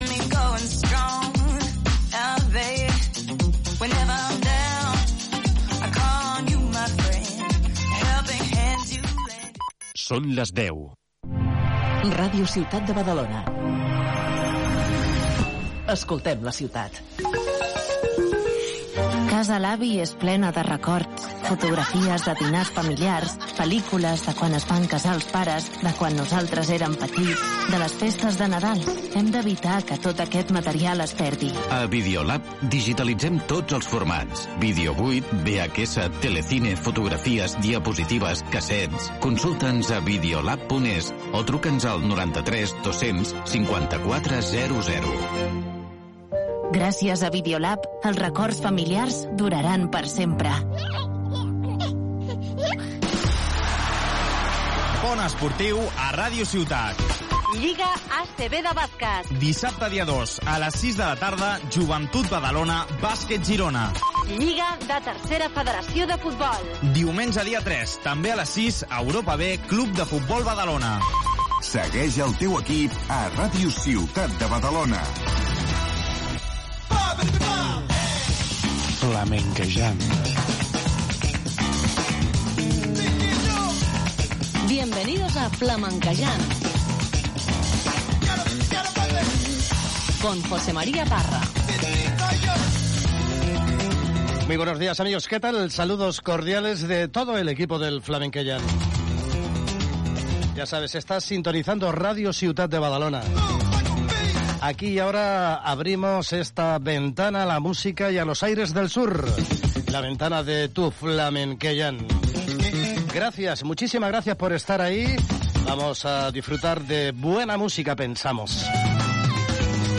1 Badalona Matí del 14/11/2024 1:00:00 Play Pause 5h ago 1:00:00 Play Pause Mais Tarde Mais Tarde Listas Like Curtido 1:00:00 A primer hora, l'informatiu i a les 9, l'anàlisi de l'actualitat amb la tertúlia política. A partir de les 10, prenem el pols de la ciutat amb un to més desenfadat. Durant dues hores, però, també es parlarà de salut, economia, futbol, cultura, cuina, humor i un llarg etcètera.